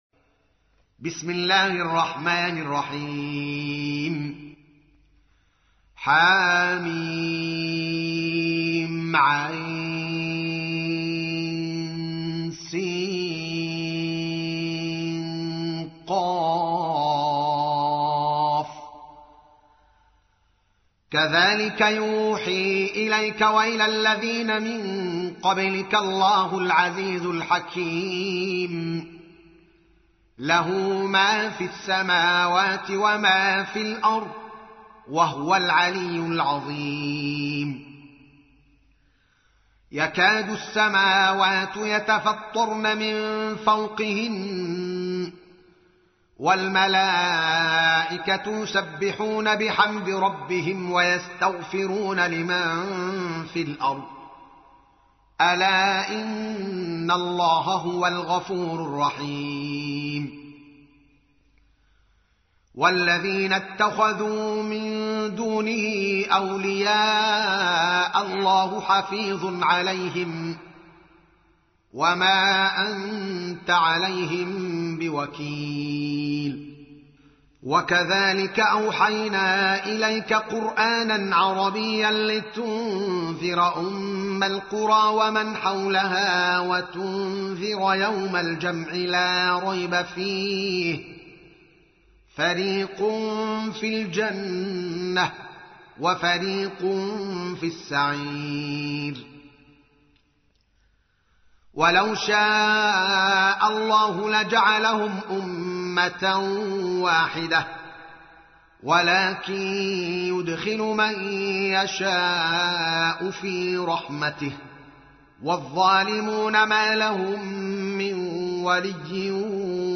تحميل : 42. سورة الشورى / القارئ الدوكالي محمد العالم / القرآن الكريم / موقع يا حسين